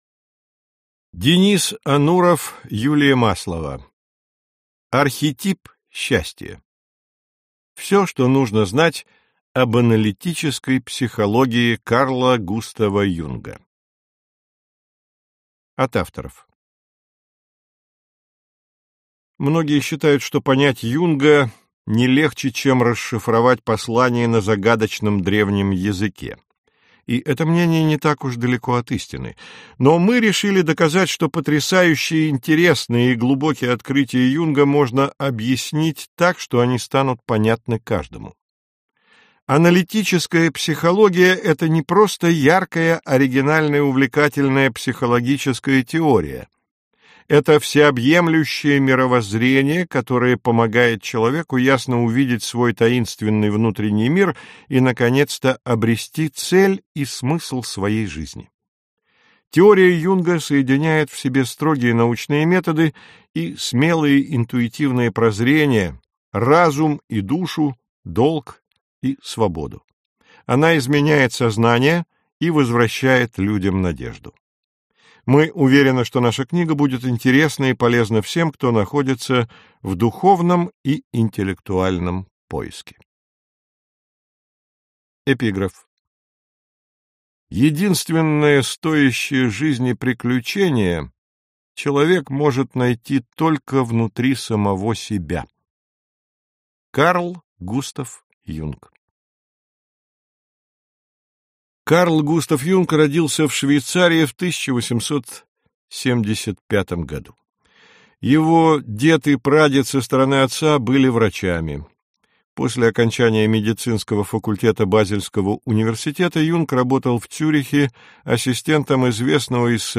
Аудиокнига Архетип счастья. Всё, что нужно знать об аналитической психологии Карла Густава Юнга | Библиотека аудиокниг